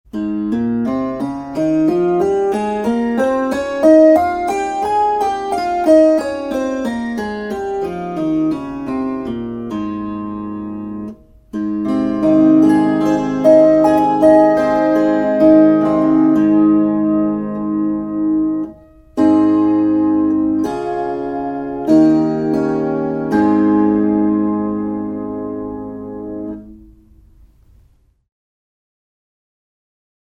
Inkkarit ampuvat d-, e-, a- ja h-vivut kumoon. Kuuntele As-duuri. b as es des Opettele duurit C G D A E F B Es mollit a e h fis cis d g c f Tästä pääset harjoittelun etusivulle.
asduuri.mp3